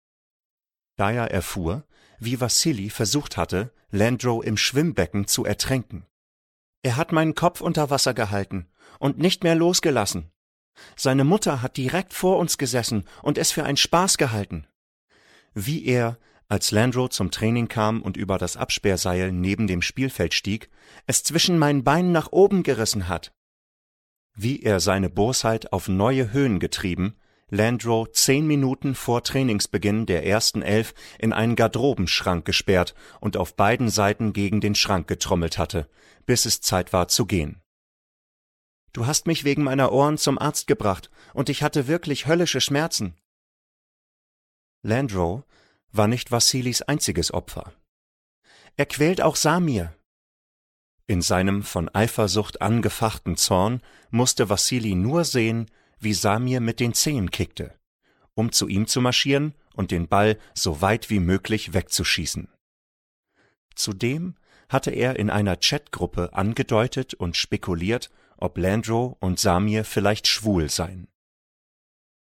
2020 | Ungekürzte Lesung